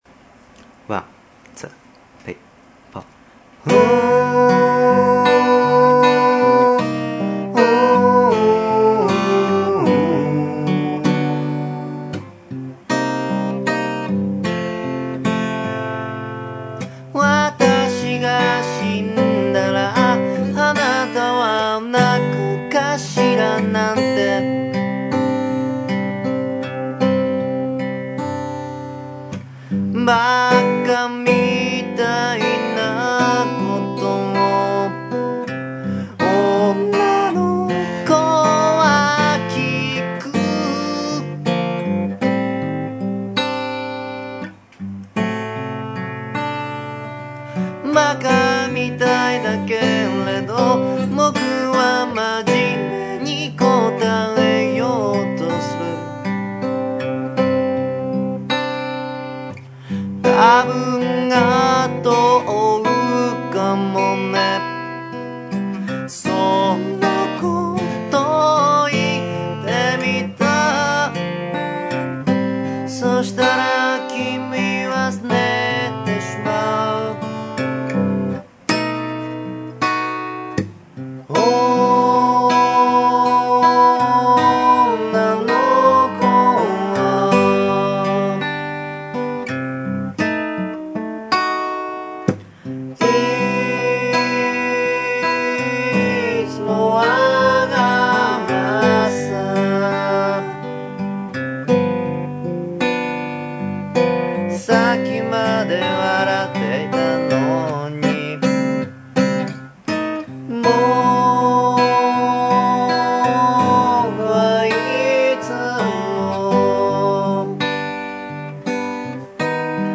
アレンジと二番は未完成のため、安易に一番のみギター弾き語りでお届けしています。